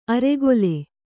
♪ aregole